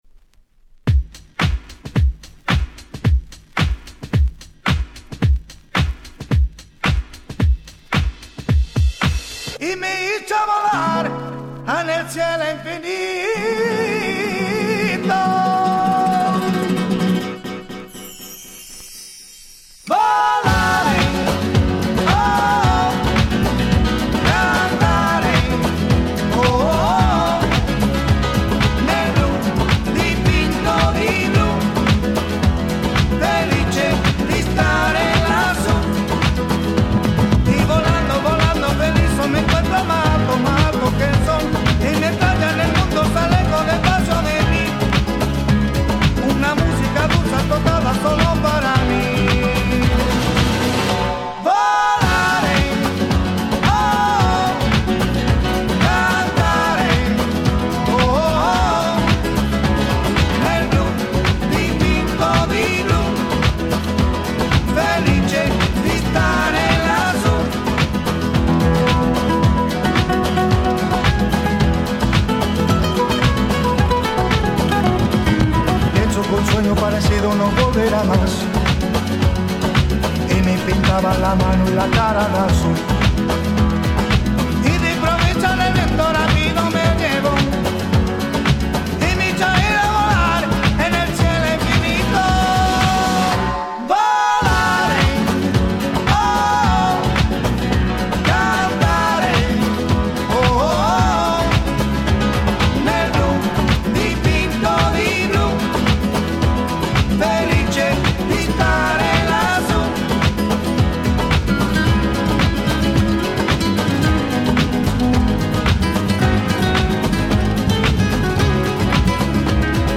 White Press Only Remix !!